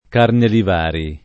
vai all'elenco alfabetico delle voci ingrandisci il carattere 100% rimpicciolisci il carattere stampa invia tramite posta elettronica codividi su Facebook Carnelivari [ karneliv # ri ] o Carnilivari [ karniliv # ri ] cogn.